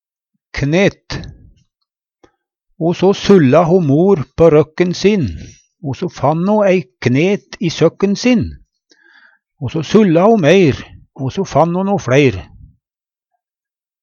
knet - Numedalsmål (en-US)